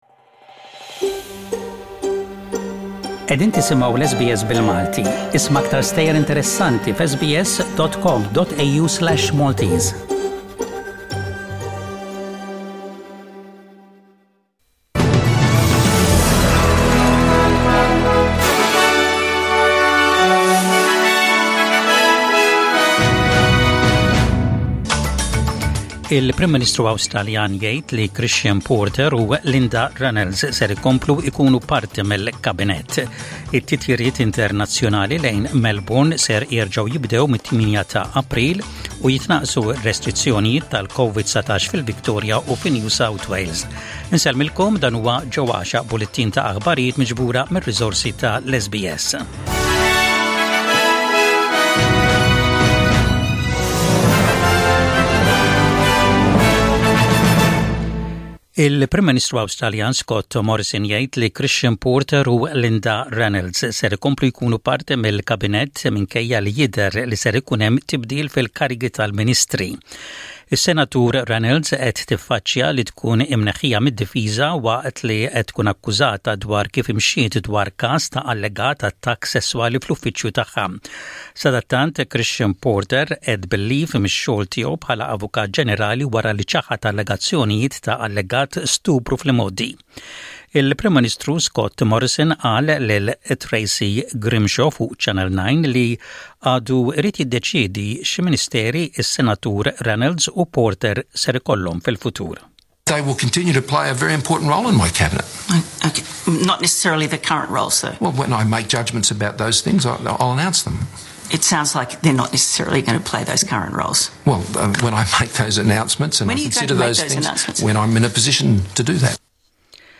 Maltese News